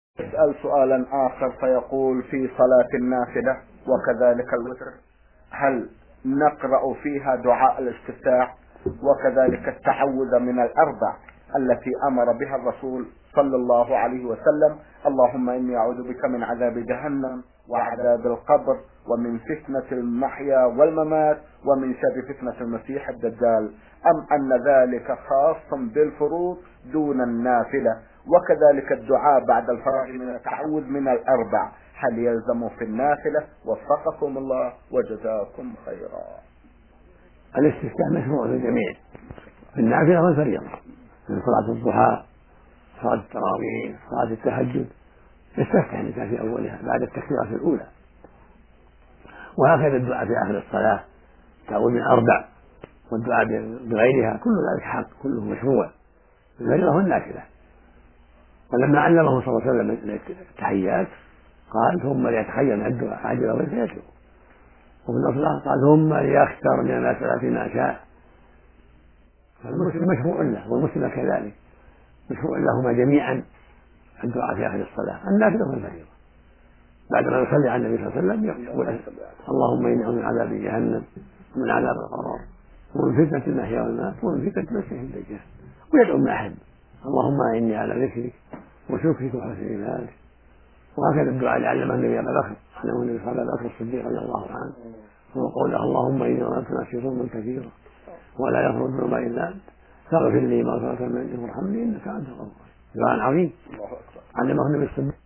شبكة المعرفة الإسلامية | الفتاوى | هل دعاء الإستفتاح خاص بالفروض فقط؟